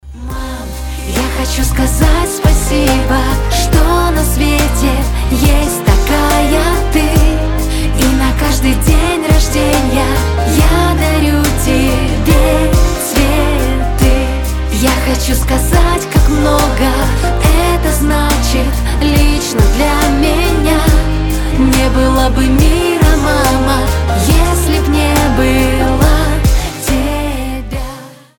поп , спокойные
душевные